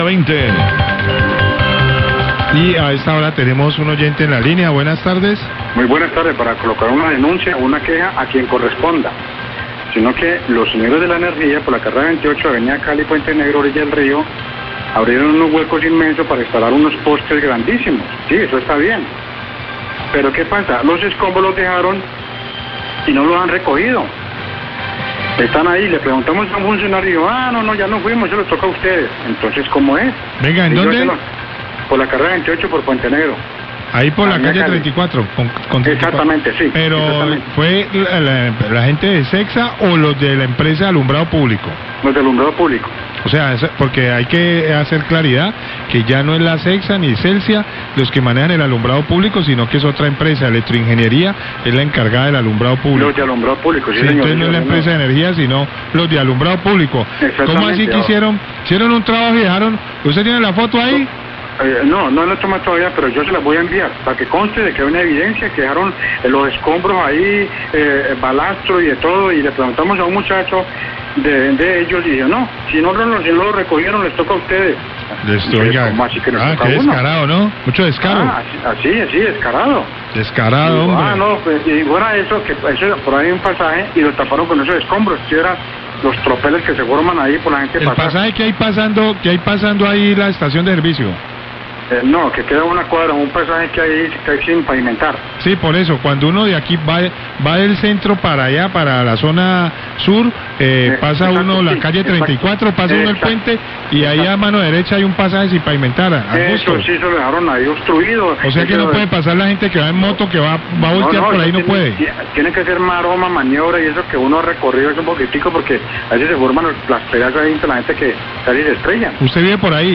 Radio
El oyente se refiere en un inicio a la empresa de energía y el periodista le aclara que la empresa de alumbrado público es diferente a la Celsia.